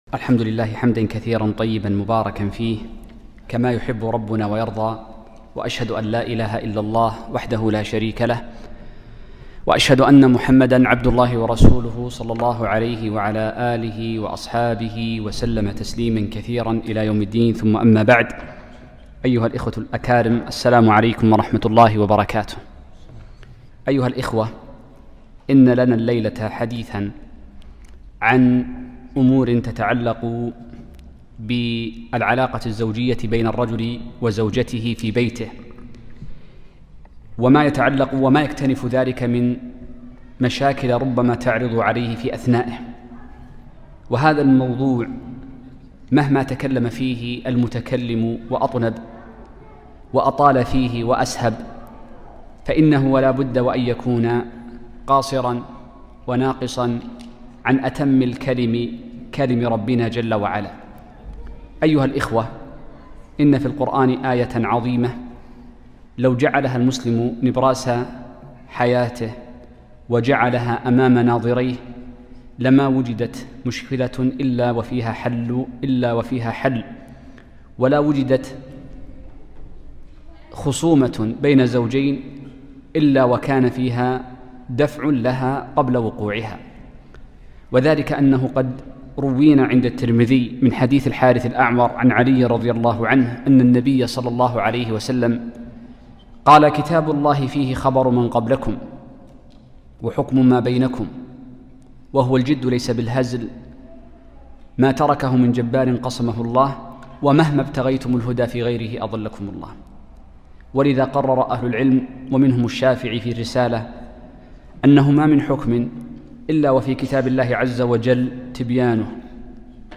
كلمة - حماية الأسرة من التفكك الأسري